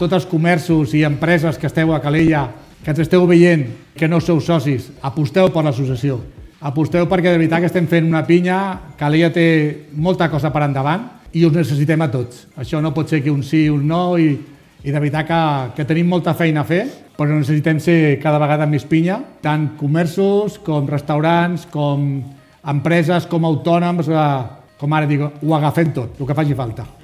Es van donar a conèixer ahir en el sorteig celebrat a l’Auditori Josep-Maria Terricabras, que es va poder seguir en directe per Ràdio Calella TV.